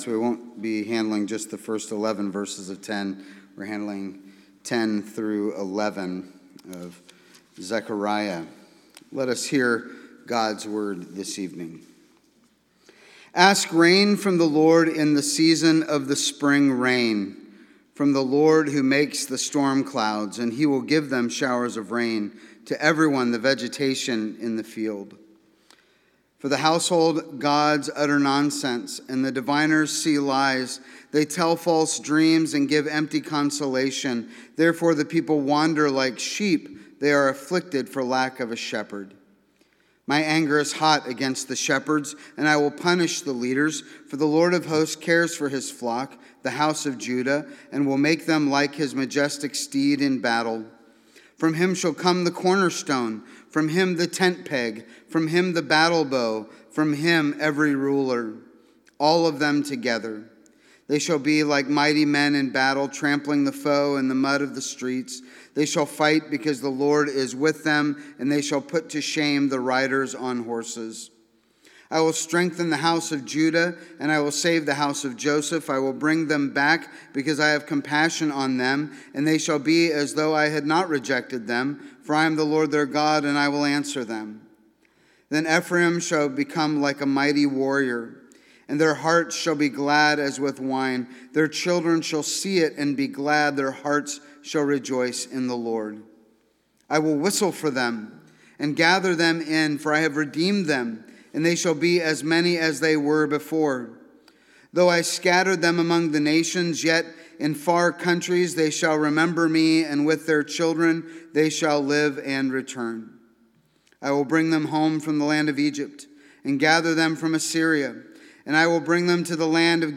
Sermon Text: Zechariah 10 and 11